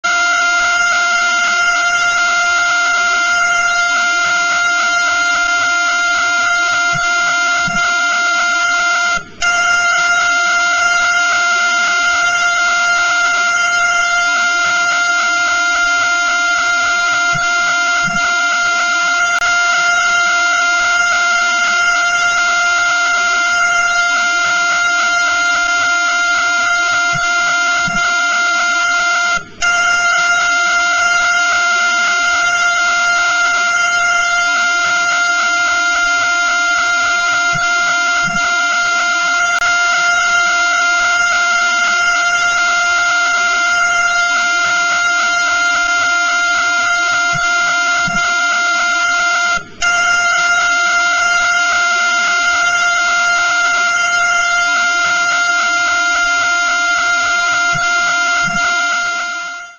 Эти уникальные аудиодорожки, преобразованные из электромагнитных колебаний, идеально подходят для создания атмосферной музыки, монтажа видеороликов, научной работы или глубокой релаксации.
Звуки пульсаров Пульсар PSR B1937 21 вращается 642 раза в секунду